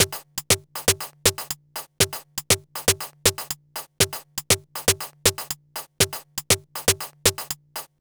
Session 14 - Percussion 02.wav